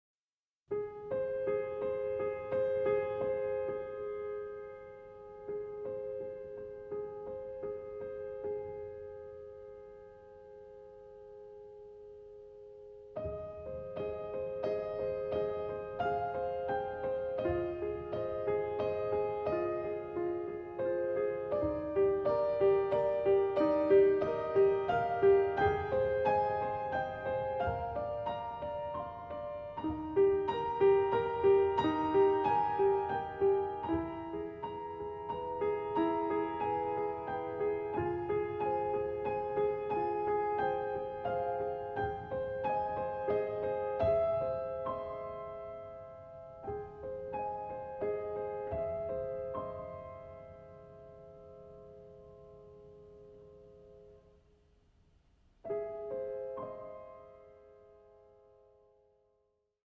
was written for solo piano
small, almost chamber-like ensembles